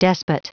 Prononciation du mot despot en anglais (fichier audio)
Prononciation du mot : despot